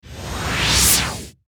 soceress_skill_forceexplosion_01_charge.ogg